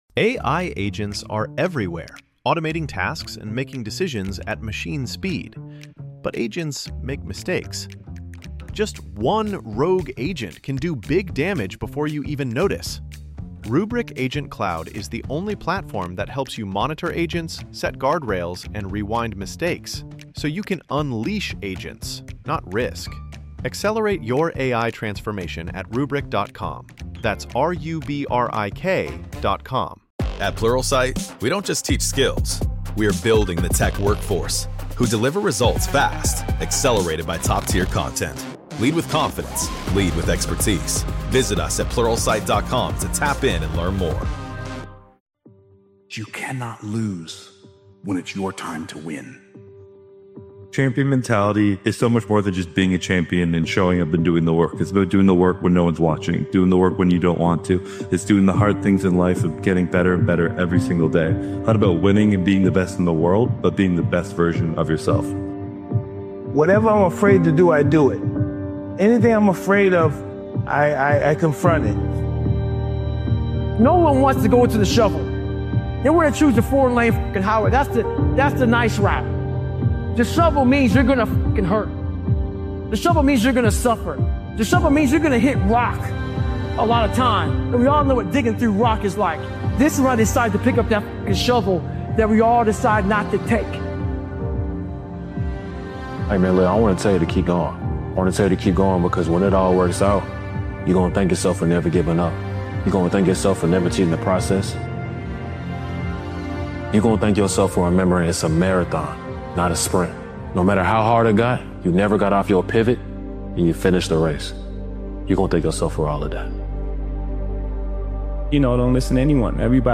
Powerful Motivational Speech episode is a relentless and empowering motivational episode created and edited by Daily Motivations. This bold motivational speeches compilation embodies the mindset of champions—the belief that success is not luck, but certainty.